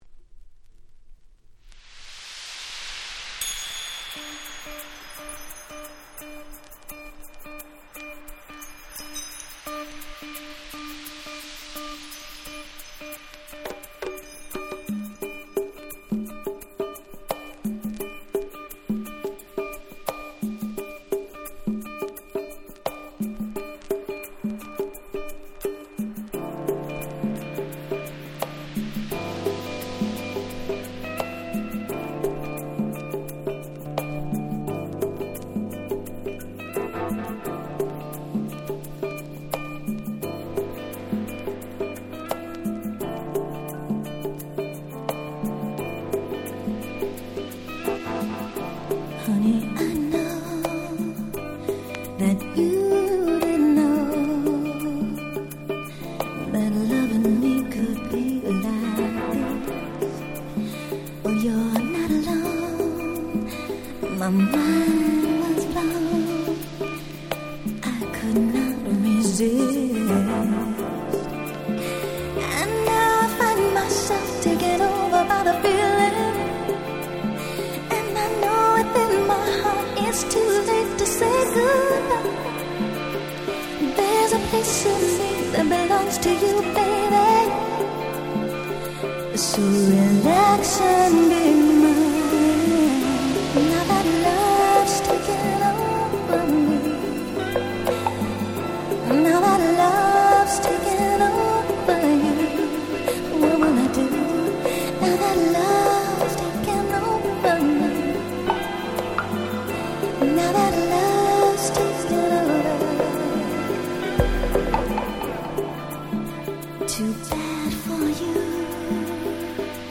92' Smash Hit R&B !!
いわゆる「Quiet Storm Version」ってヤツです。
BeatがなくてChillに特化した甘〜いバージョンです！
90's クワイエットストーム プロモオンリー